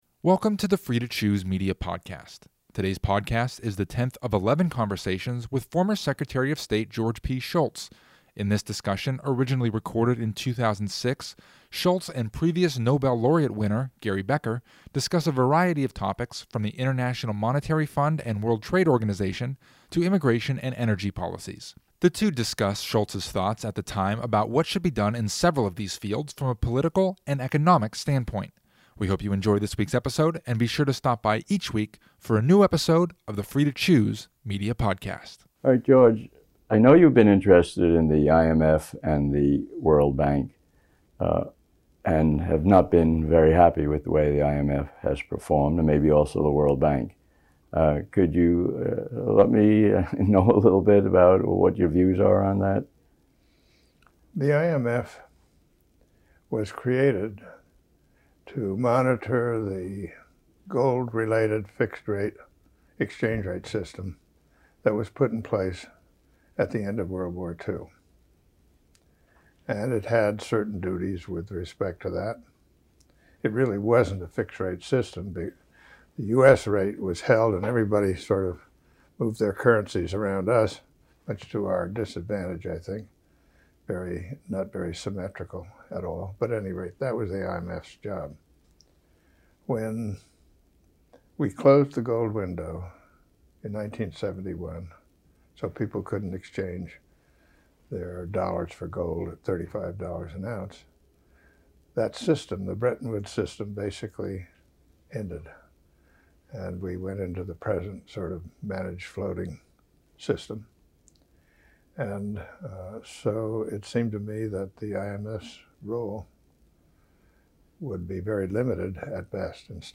This 2006 conversation between former Secretary of State George P. Shultz and previous Nobel Laureate winner Gary Becker takes a hard look at the politics behind several economic areas from the International Monetary Fund to energy.